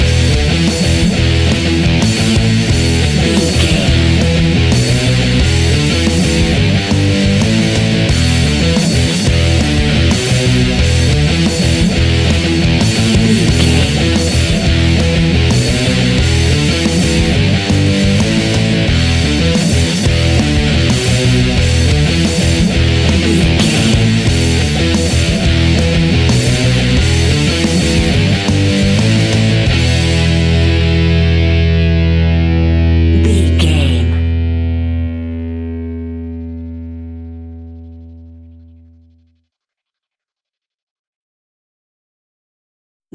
Hard and Powerful Metal Rock Music Cue 30 Sec.
Epic / Action
Aeolian/Minor
hard rock
heavy metal
distortion
Rock Bass
heavy drums
distorted guitars
hammond organ